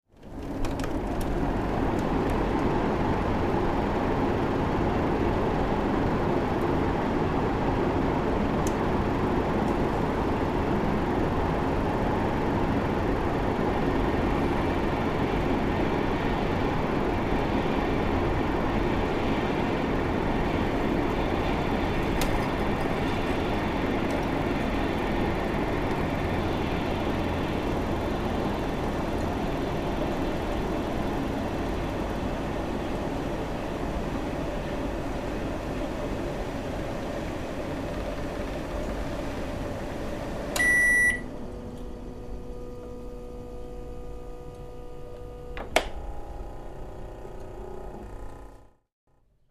Air Conditioner, Indoor Body, Start, Stop